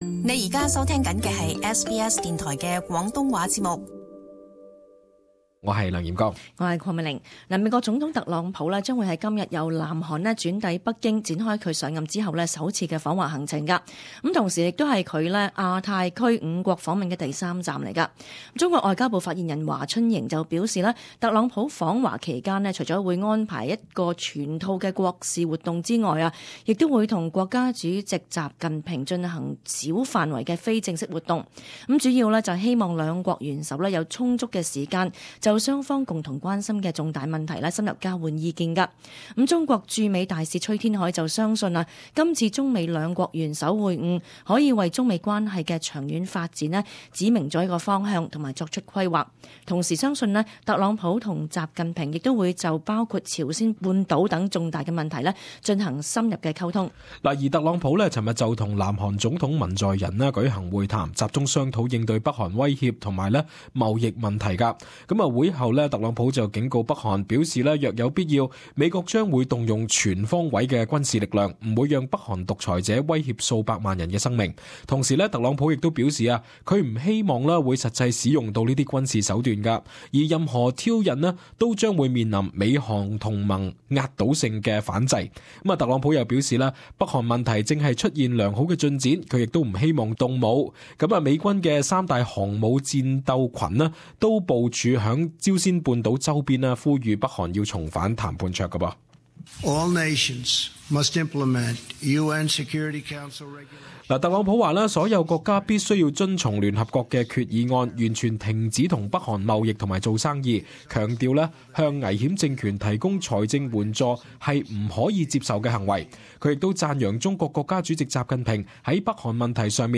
[时事报导]特朗普今日展开上任后首次访华行程